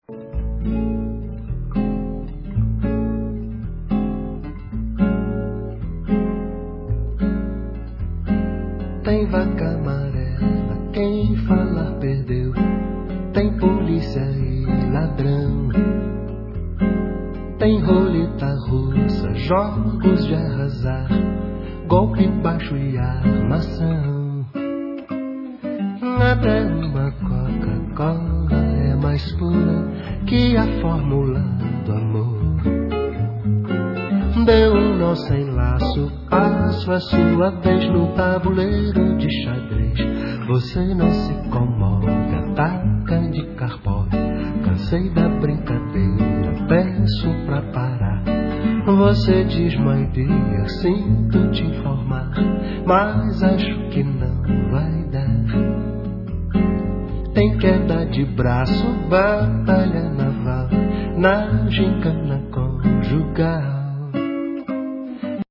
Guitarist, Composer, Lyricist